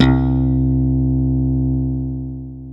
KW FUNK  C 2.wav